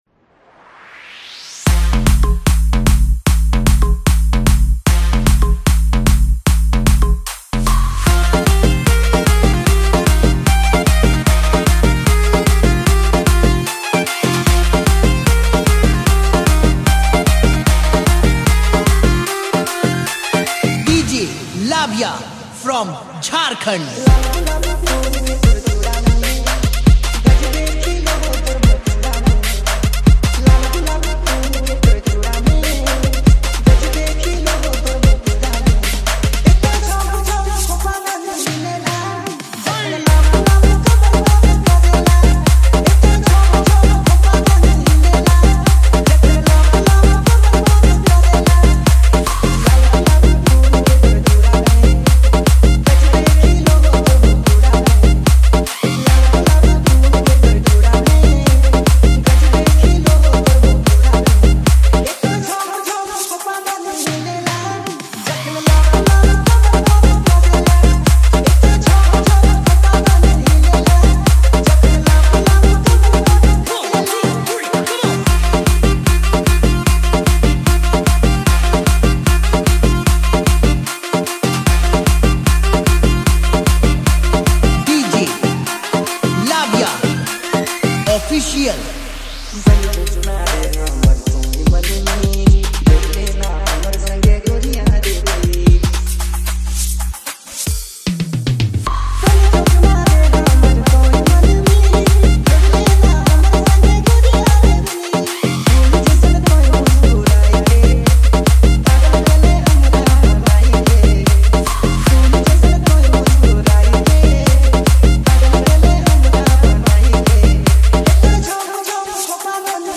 Category : Bhojpuri Remix Song